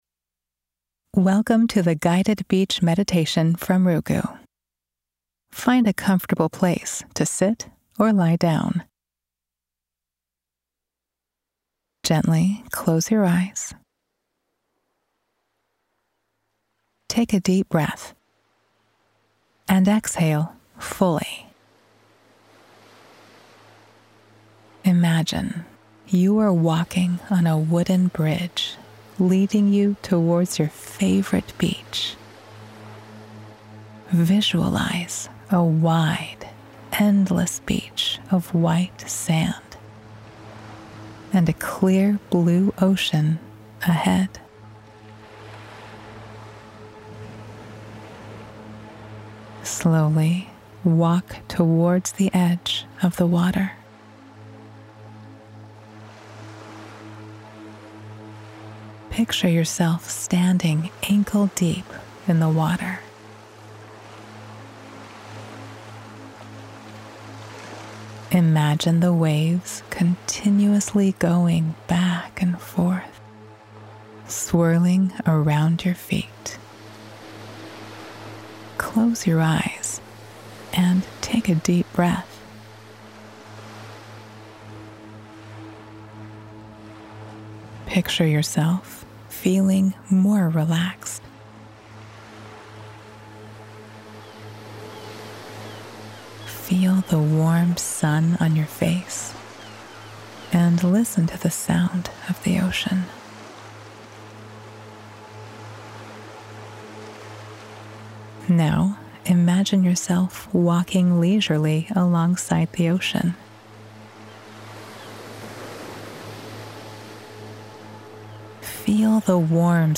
Guided Imagery Beach